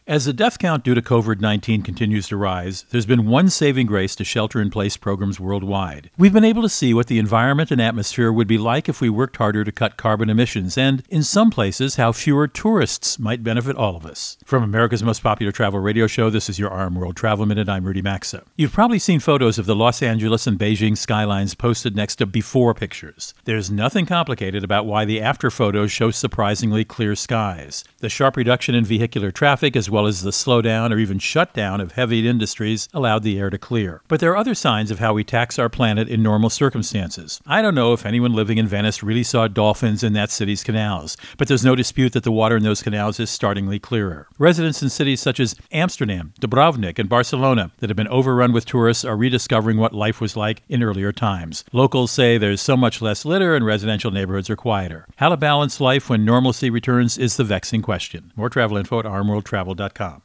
America's #1 Travel Radio Show
Tuesday, 9 Jun 20 .. Co-Host Rudy Maxa | Saving Grace of Sheltering in Place